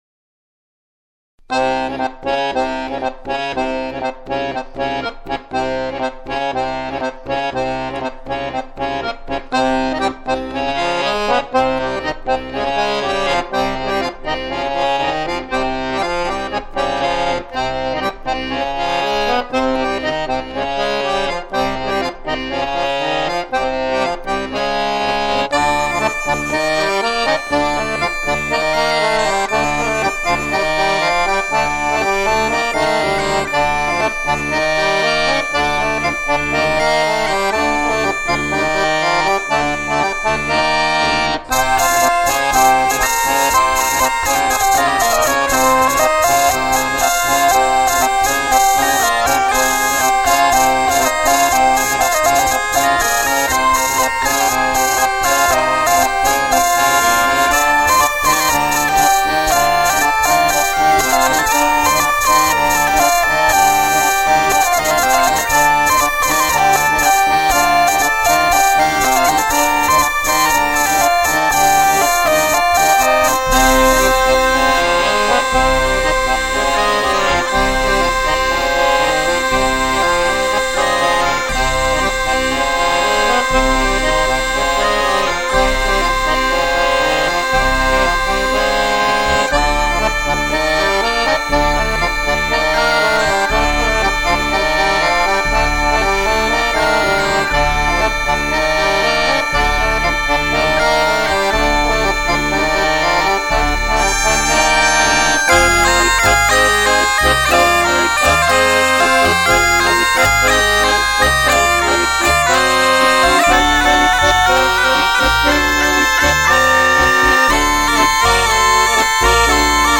2 Time Bourree